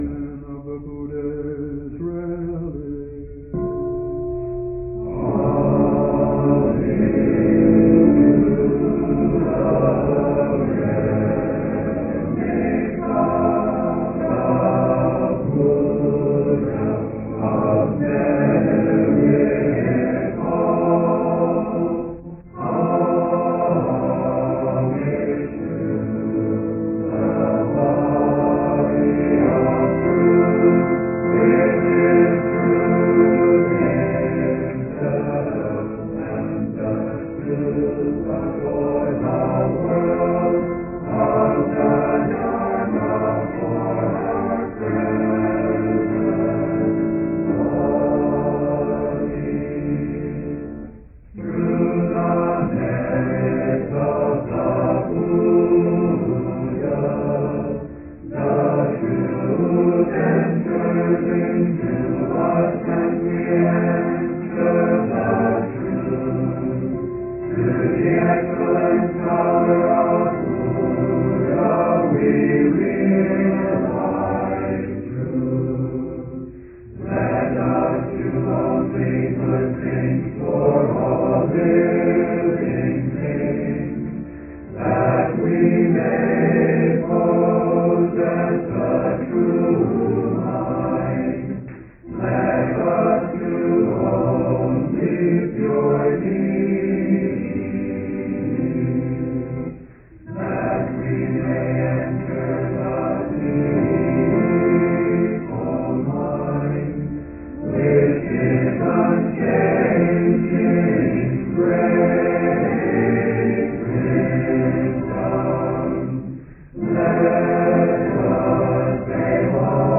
Adoration of the Buddha’s Relics (chanting 03:06)